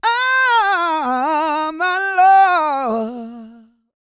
E-GOSPEL 261.wav